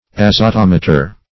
Search Result for " azotometer" : The Collaborative International Dictionary of English v.0.48: Azotometer \Az`o*tom"e*ter\, n. [Azote + -meter.]